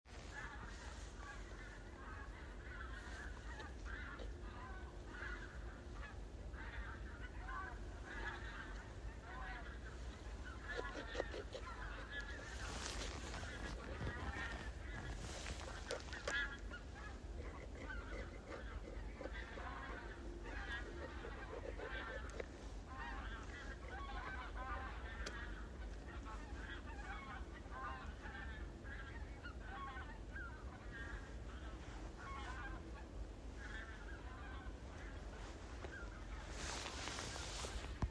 Birds -> Geese ->
Greater White-fronted Goose, Anser albifrons